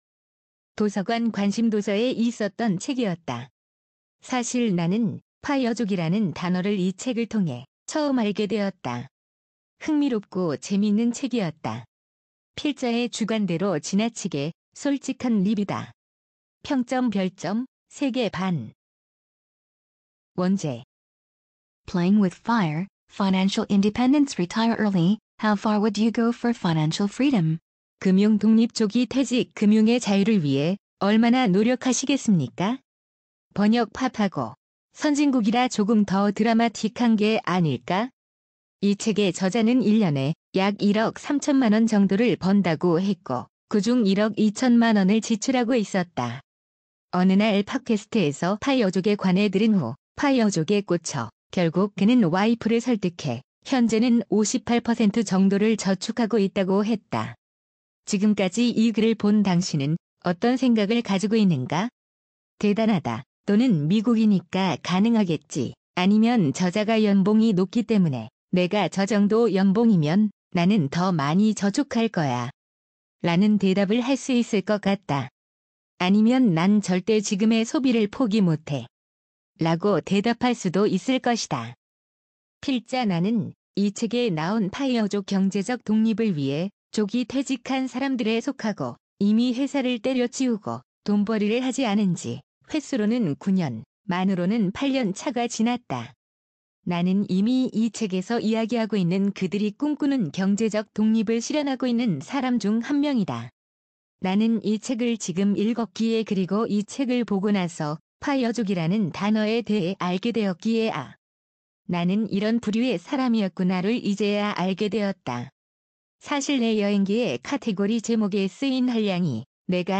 눈으로 읽기 귀찮으신 분들에게 들어보라고 만들어본 오디오 리뷰입니다.